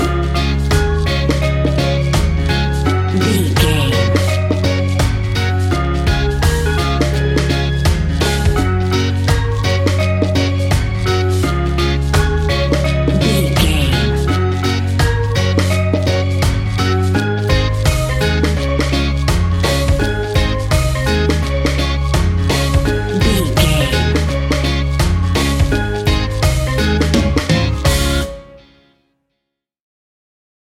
Uplifting
Ionian/Major
D♭
steelpan
drums
percussion
bass
brass
guitar